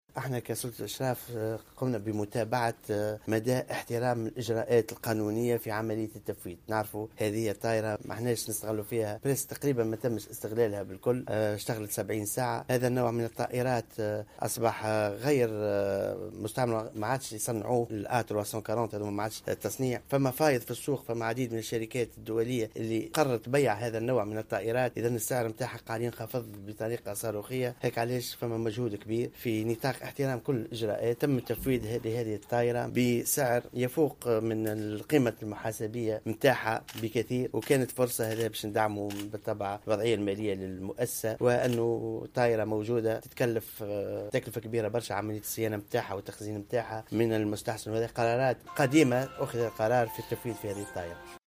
قال وزير النقل أنيس غديرة في تصريح لمراسل الجوهرة اف ام اليوم الإثنين 5 ديسمبر 2016...